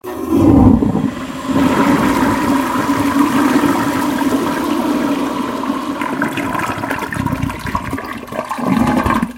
Toilet flushing - Смыв унитаза
Отличного качества, без посторонних шумов.
177_toilet-flushing.mp3